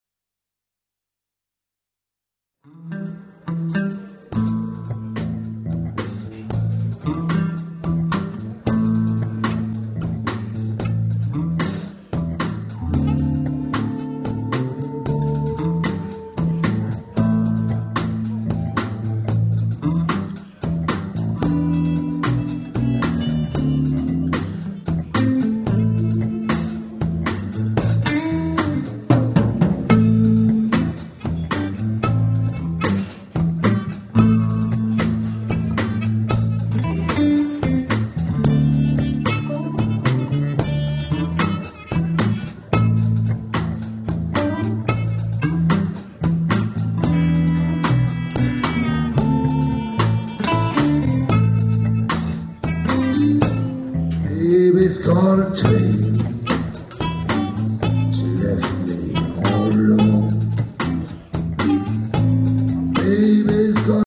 harmonicas
basse et chant
batterie
chant, guitare, flûte
Des extraits, (30 secondes environ) du concert enregistré le 3 Mars 2000
au Relais de la Reine Margot (Longvic, Côte d'or) :